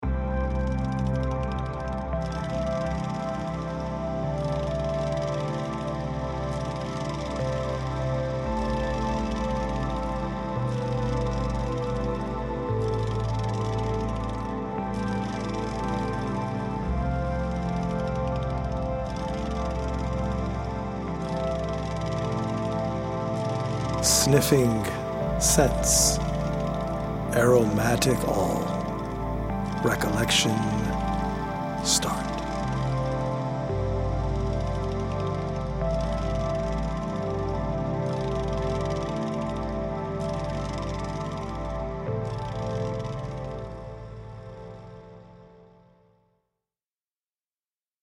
healing Solfeggio frequency music